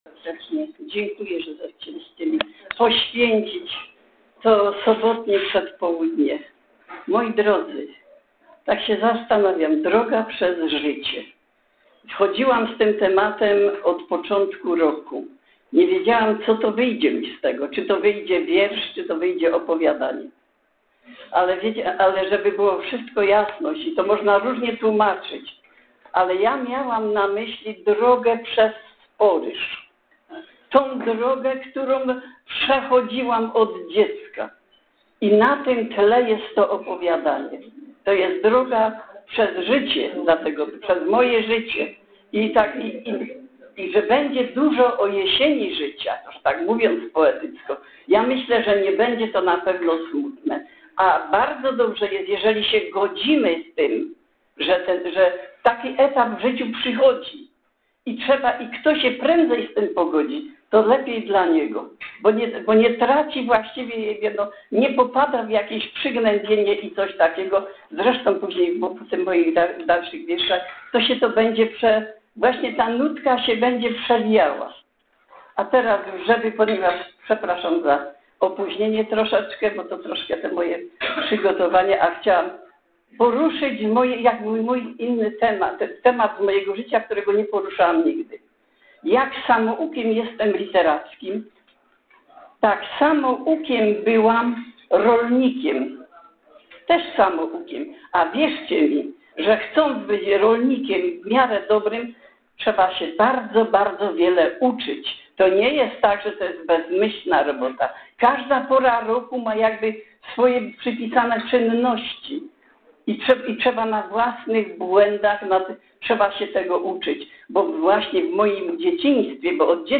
w siedzibie TMZŻ